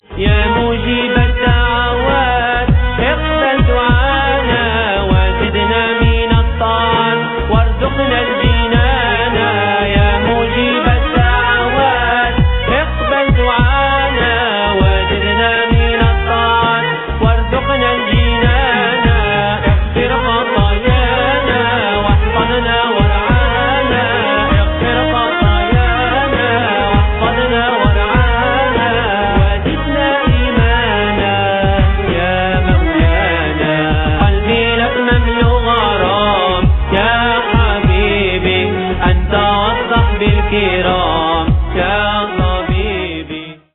اناشيد